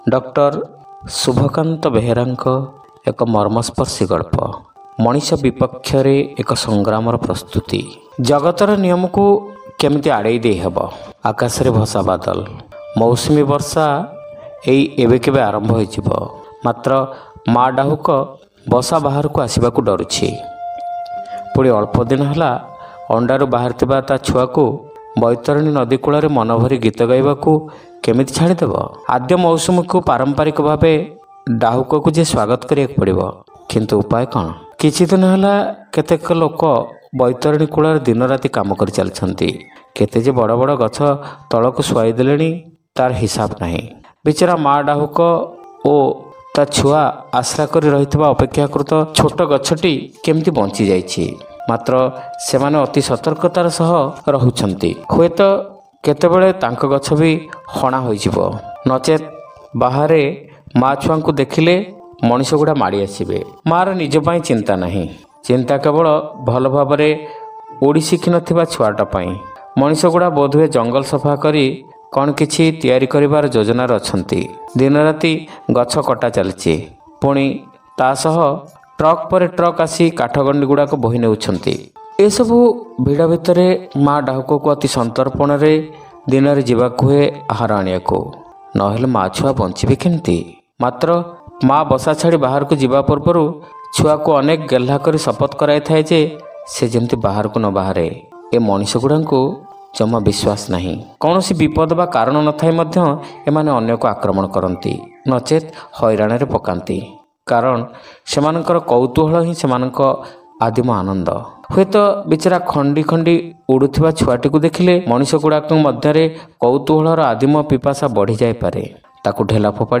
Audio Story : Manisha Bipakhya re Eka Sangramara Prastuti